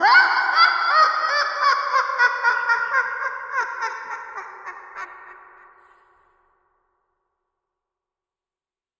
evil_laugh_single_0m09s
evil female fx laugh laughter panto processed sfx sound effect free sound royalty free Funny